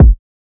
Kick 3.wav